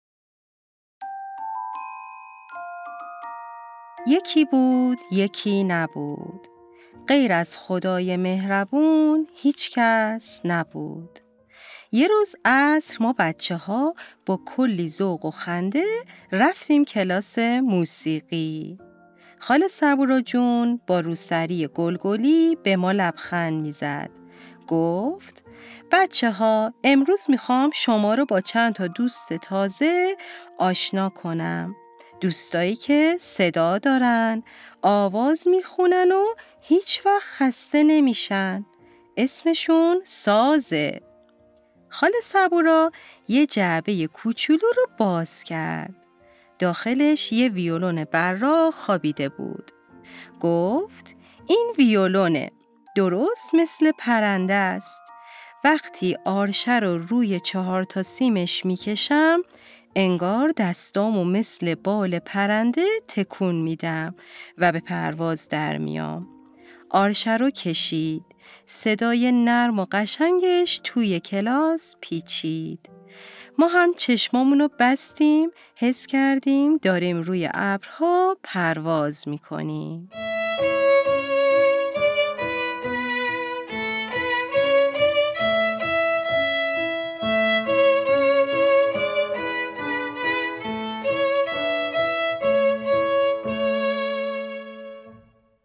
• نسخه صوتی با صدای واقعی سازها